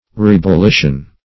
Search Result for " rebullition" : The Collaborative International Dictionary of English v.0.48: Rebullition \Re`bul*li"tion\ (r[=e]`b[u^]l*l[i^]sh"[u^]n), n. The act of boiling up or effervescing.